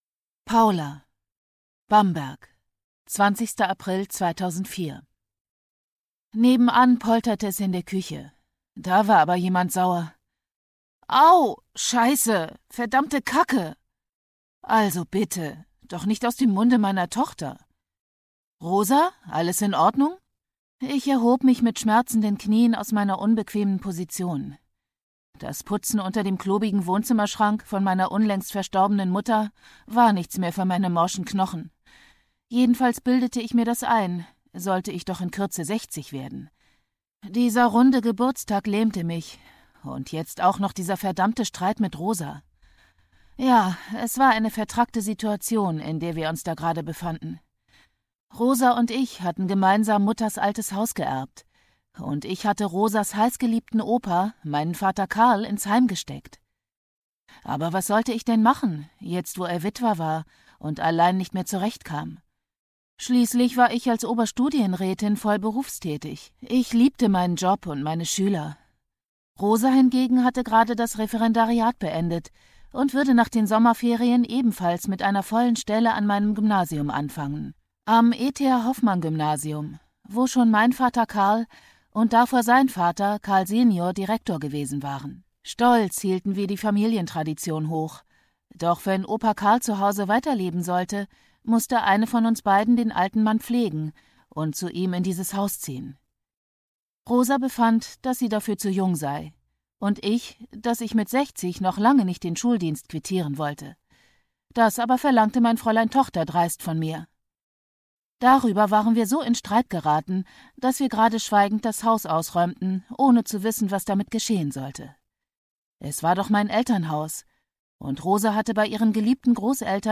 2022 | Ungekürzte Lesung